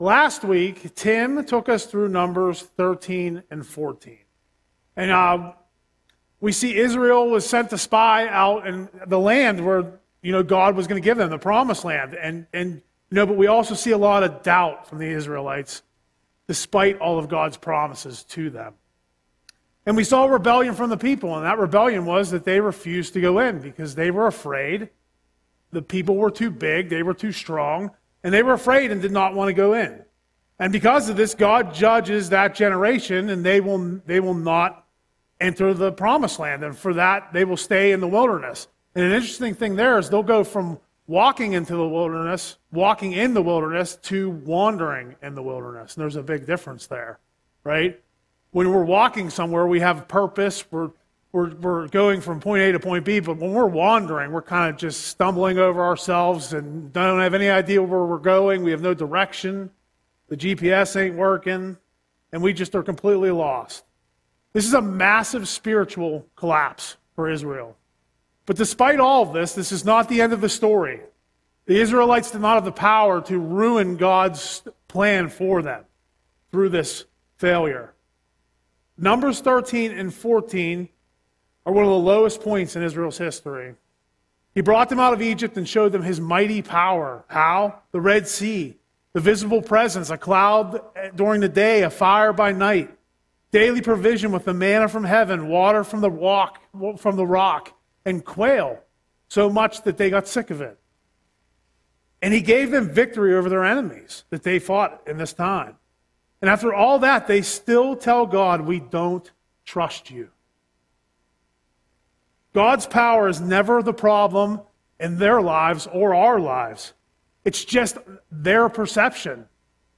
Audio Sermon - March 25, 2026